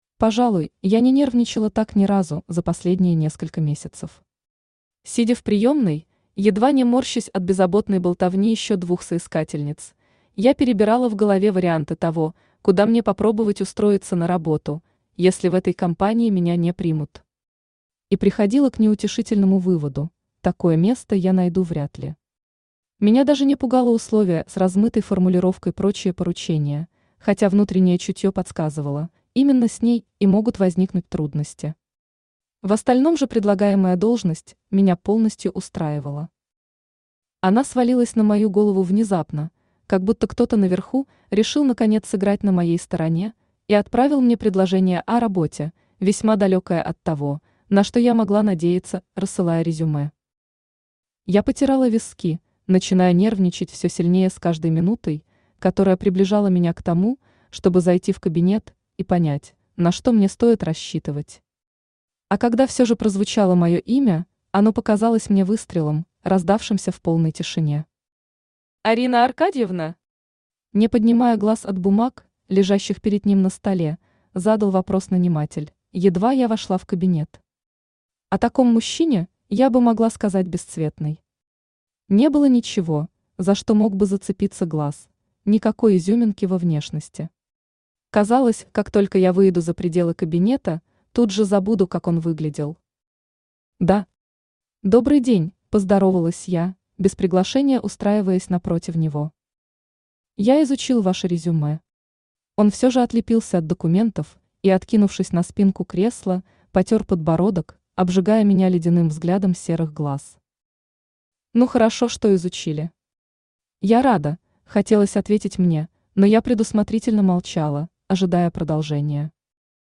Aудиокнига Его любимая кукла Автор Тати Блэк Читает аудиокнигу Авточтец ЛитРес.